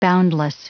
Prononciation du mot boundless en anglais (fichier audio)
Prononciation du mot : boundless